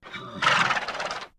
Whinnies
Horse Whinnies & Blows 7